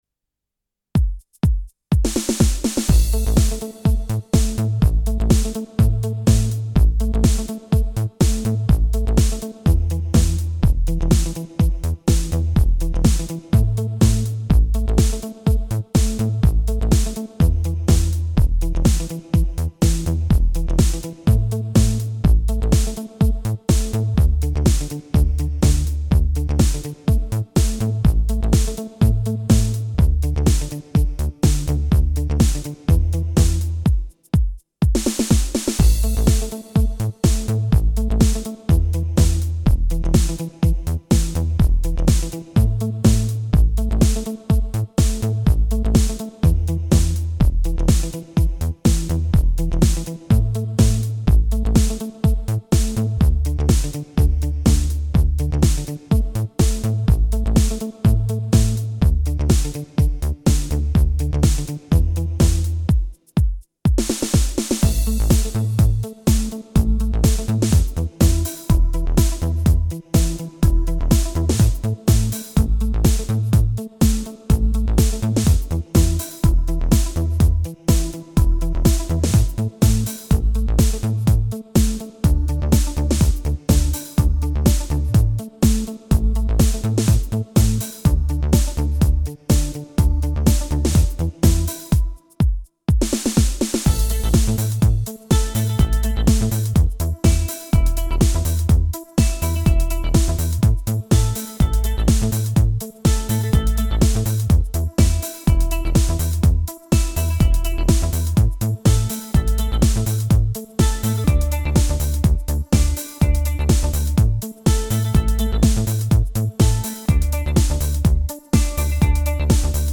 Het-luchtballonlied-instrumentaal.mp3